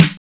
SNARE122.wav